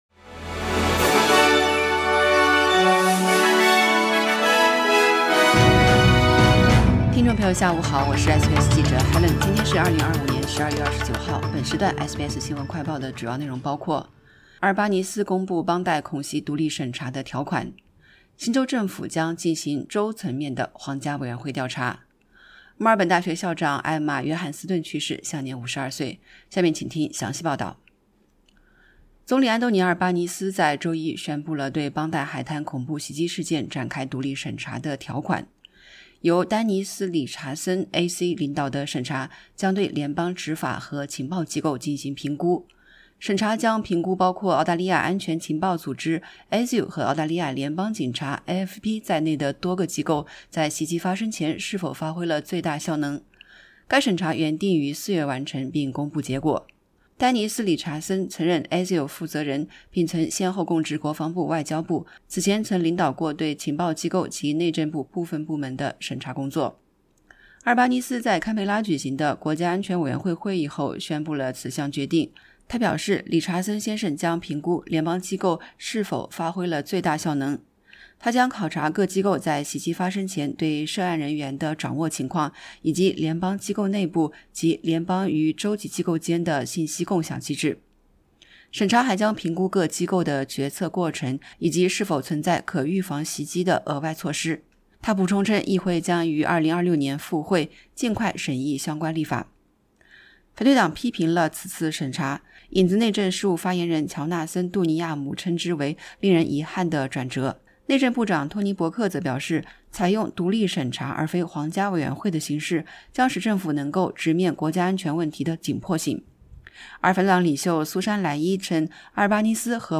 【SBS 新闻快报】总理公布邦迪恐怖袭击事件独立审查的条款